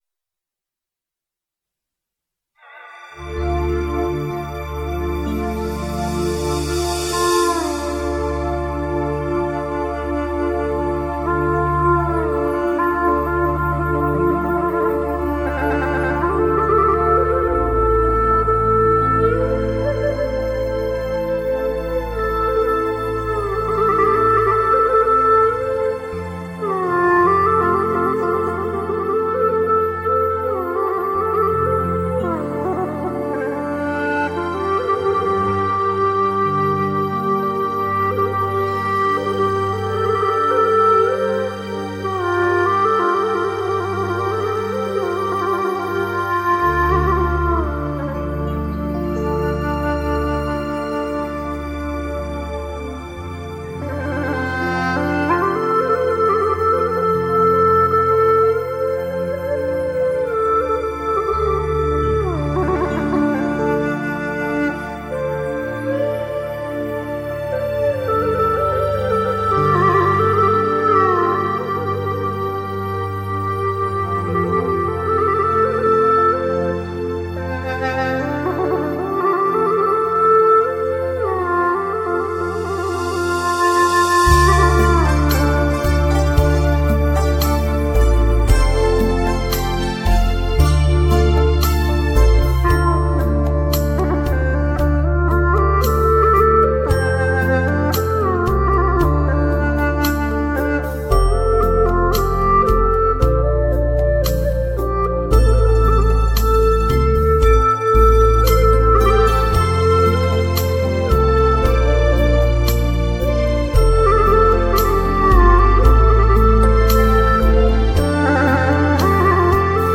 葫芦丝曲目 欣赏下载 曲目调式 伴奏下载 音乐格式